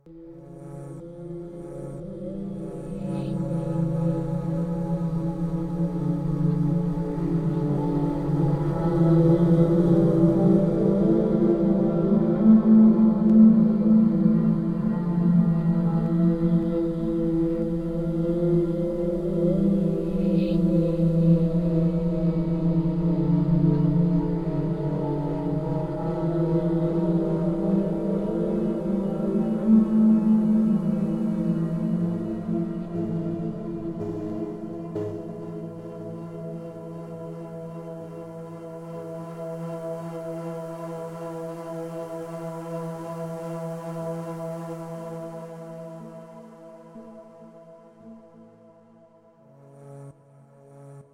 swell pad
Perfect for ambience, atmosphere, background.
ambience atmosphere background brooding cinematic dark eerie intro sound effect free sound royalty free Movies & TV